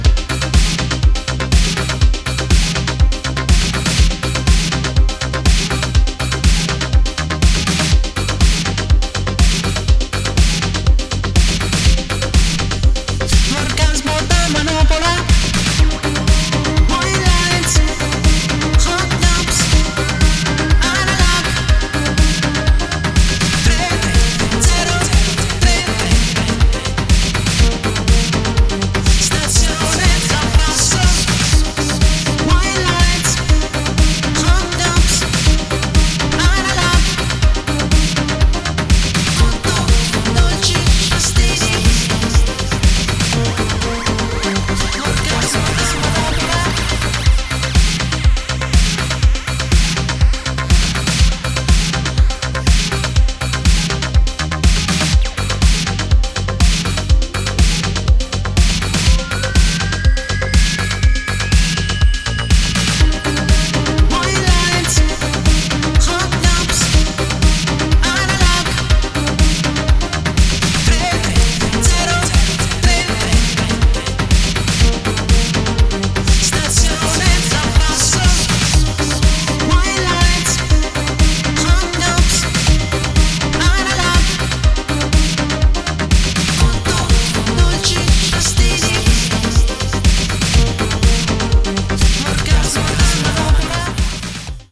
androgynous voice
Disco Electro Italo Pop